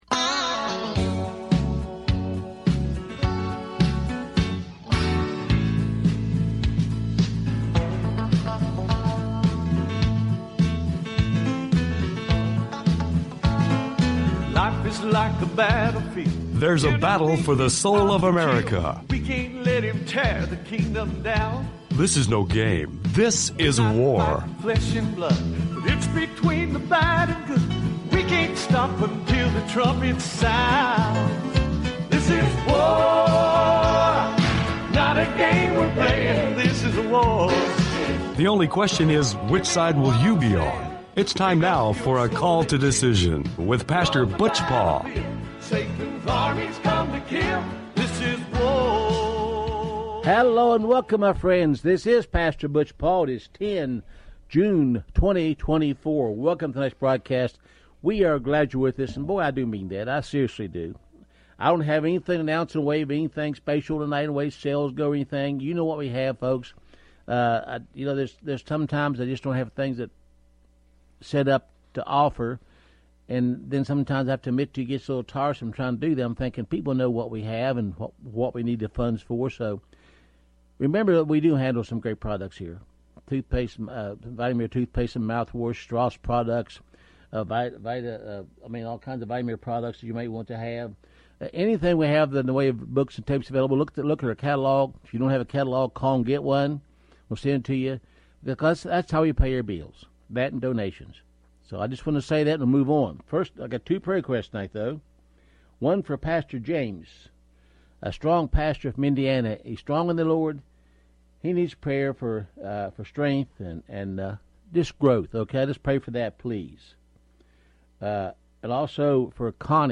Talk Show Episode, Audio Podcast, Call To Decision and Title: Coming on , show guests , about call to decision, categorized as History,Military,News,Politics & Government,Christianity,Society and Culture